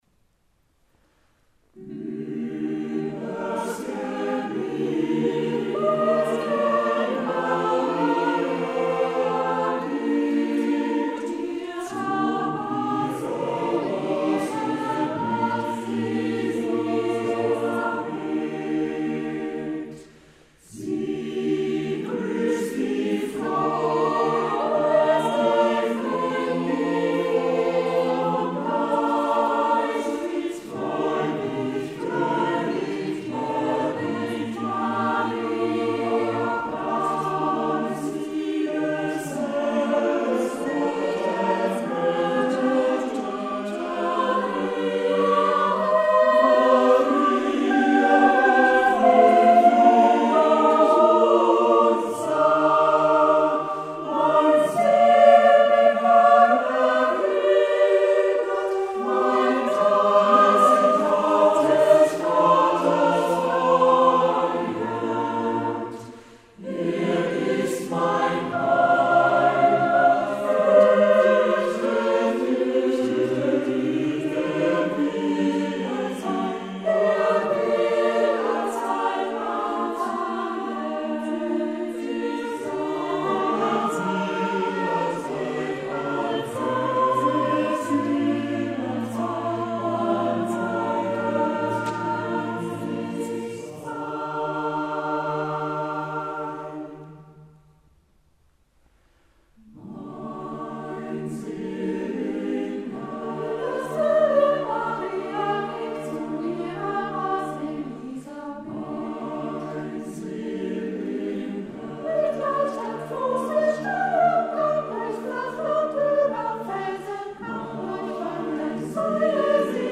Konzertmitschnitt vom 29.12.2017, Kulturkirche Epiphanias Mannheim